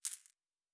beyond/Assets/Sounds/Fantasy Interface Sounds/V 2.0/Coin 01.wav at main
Coin 01.wav